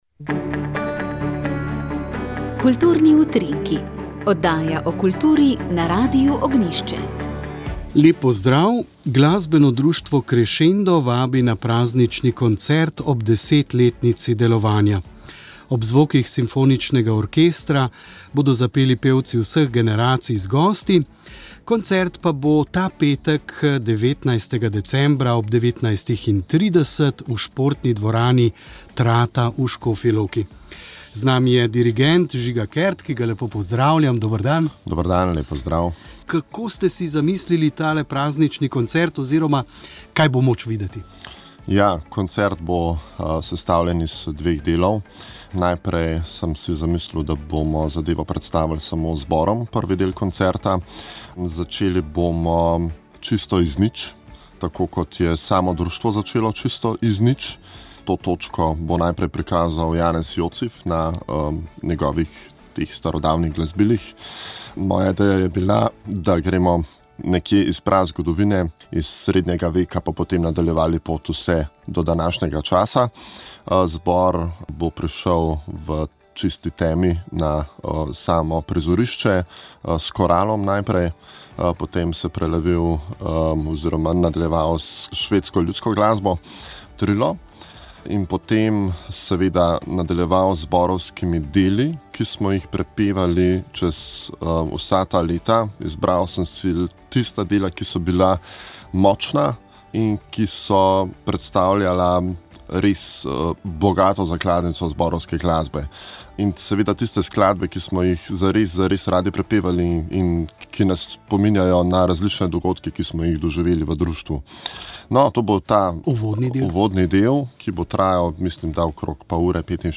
Slišali ste pogovor z bogoslovcem, pripoved o odločitvi in pričakovanjih.